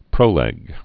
(prōlĕg)